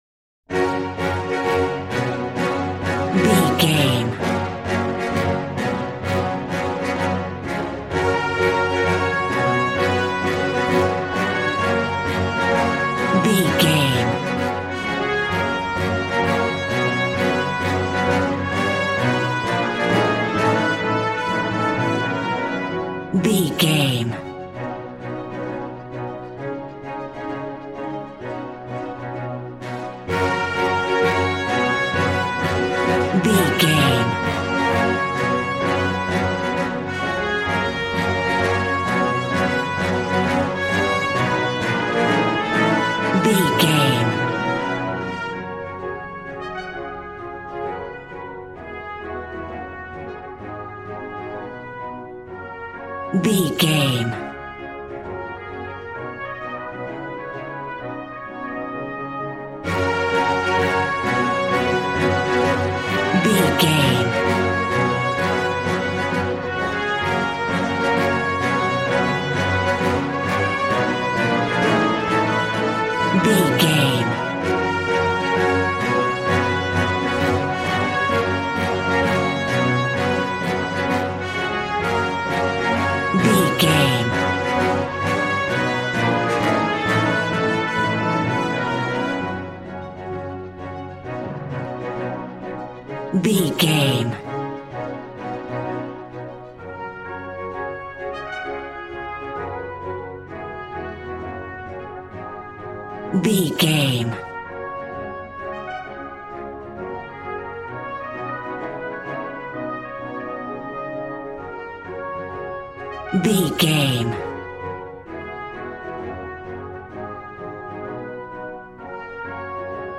Ionian/Major
G♭
dramatic
epic
percussion
violin
cello